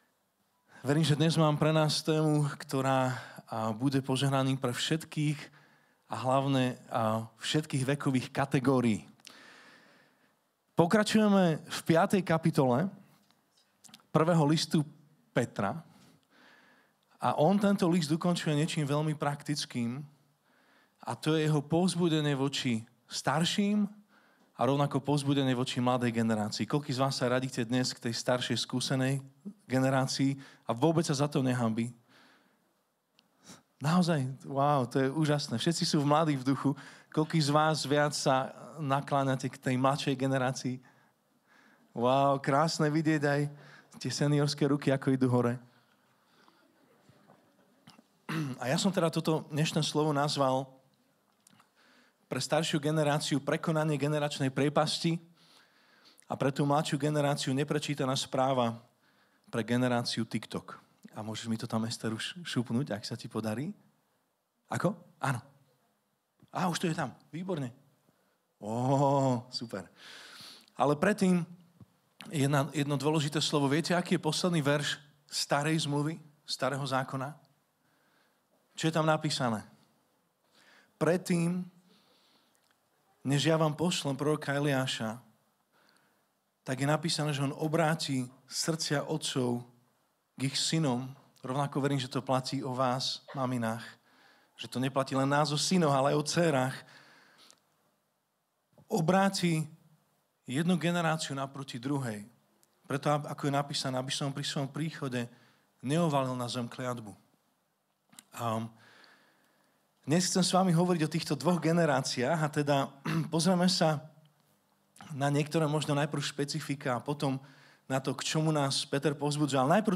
Praktické vyučovanie z 1.Petrovho listu 5. kapitoly v rámci série „Až do cieľa.“ Hovorili sme o tom, ako je pokora základom pre medzigeneračný dialóg a vzájomnú úctu.